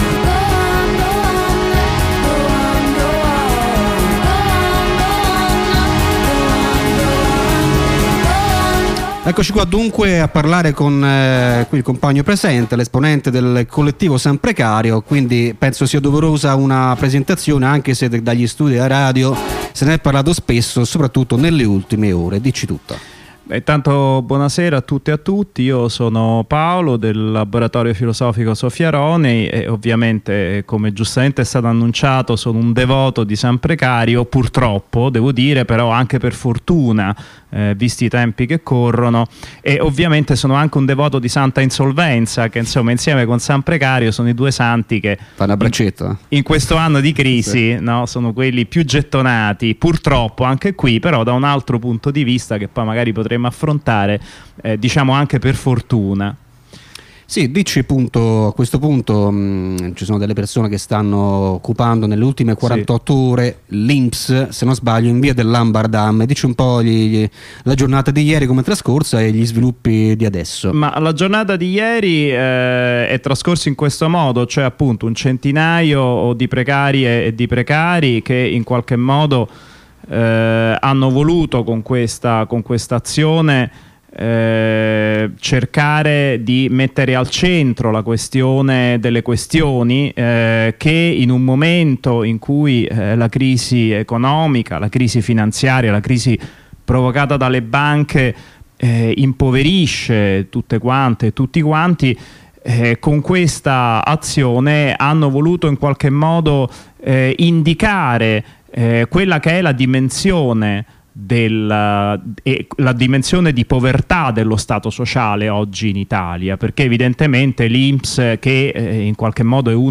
Una chiacchierata negli studi di Radio Onda Rossa con un compagno della Rete San Precario sull' occupazione dell' Inps iniziata il 22 Dicembre,sulla precarietà, diritti,reddito garantito.....